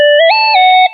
Category: LG Ringtones